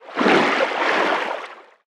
Sfx_creature_featherfish_swim_slow_04.ogg